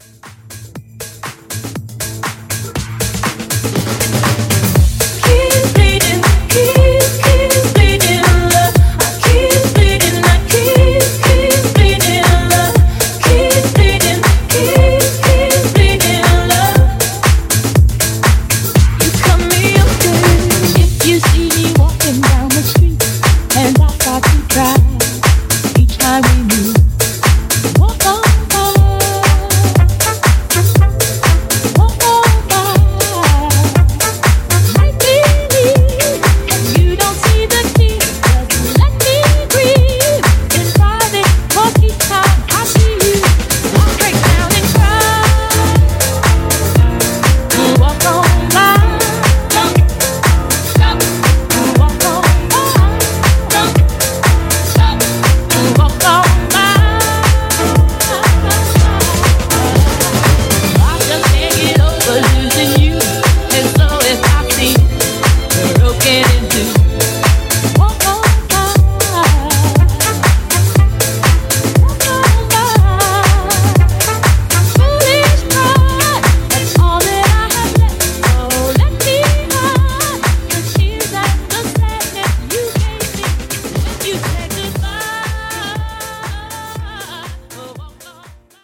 Genres: BOOTLEG , DANCE
Clean BPM: 120 Time